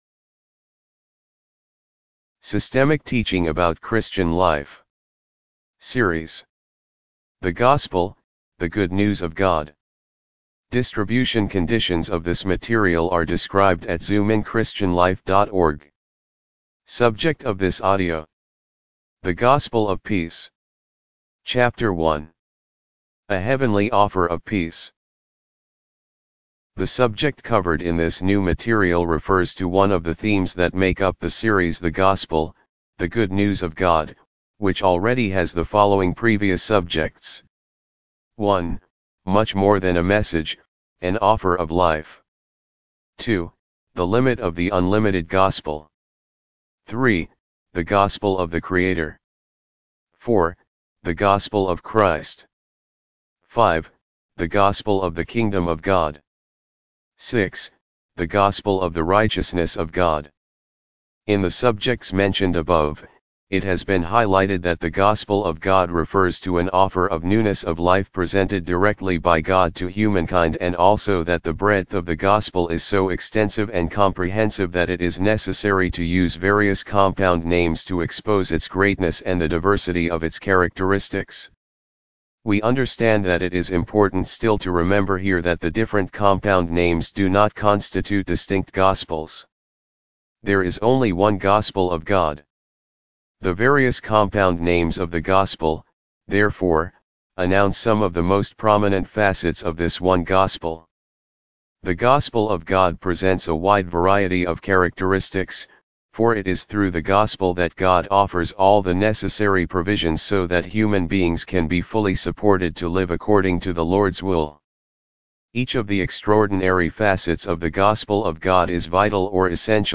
Audibooks - Systemic Teaching about Christian Life Audio Book divided into chapters.